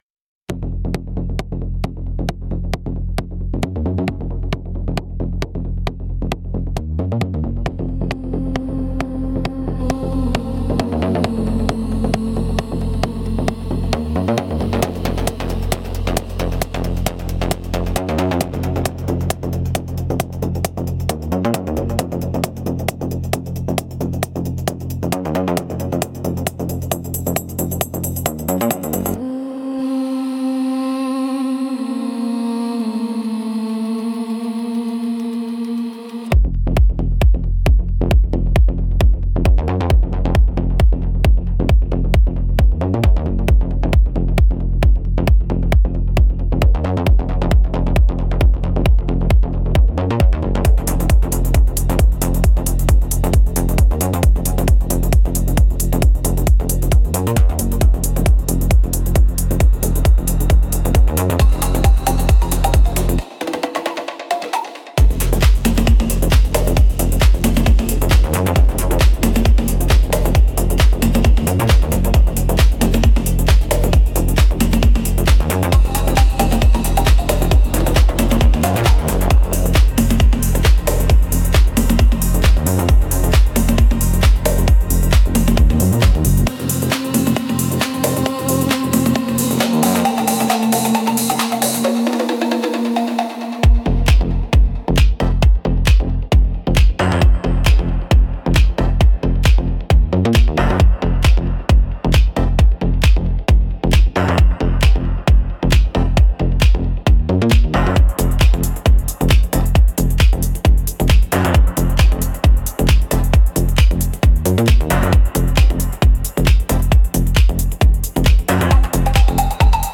Instrumentals - Smoke Signal Transmission